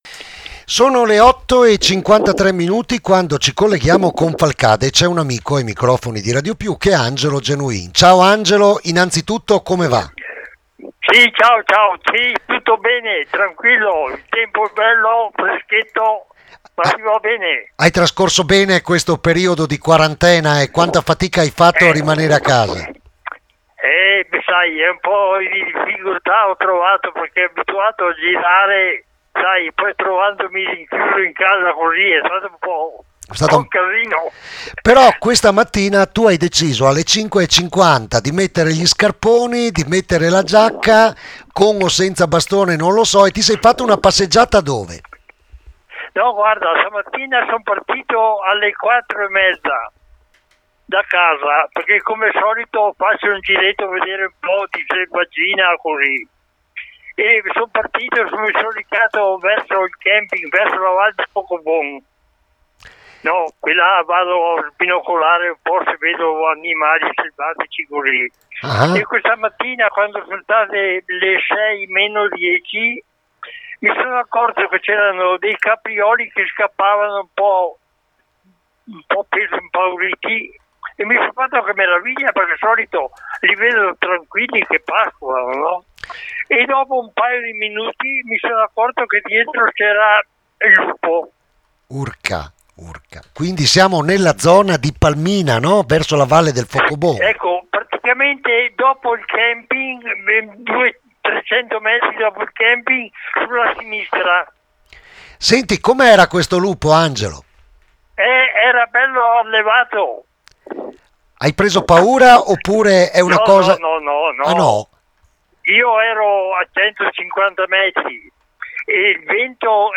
Alle 5.50 non lontano dal camping la sorpresa e l’emozione raccontata ai microfoni di RADIO PIU